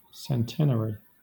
Ääntäminen
Southern England